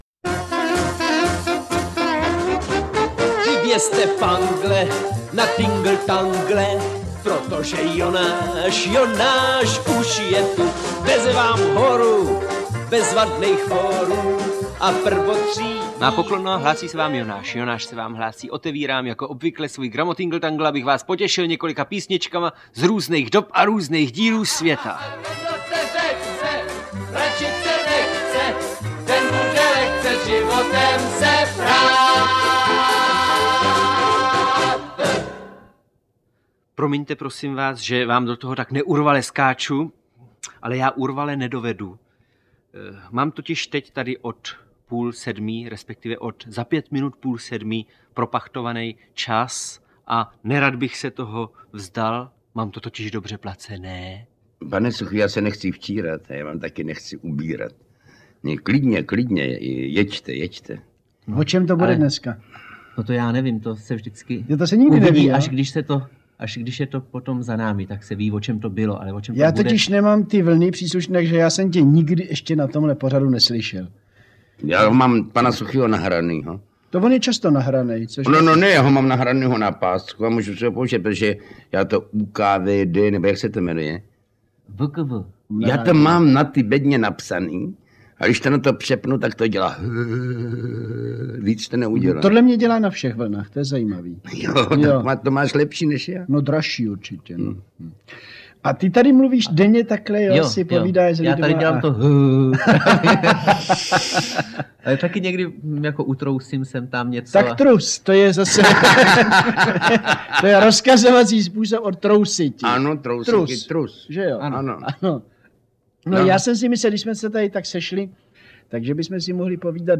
Interpret:  Jan Werich
Gramotingltangl byl pořad Jiřího Suchého, vysílaný od pondělí 4. října 1965 v podvečer Československým rozhlasem na tehdy novém vlnovém rozsahu velmi krátkých vln.
Vedle Suchého, v tomto pořadu chytrých úvah doplňovaných hudbou z gramofonových...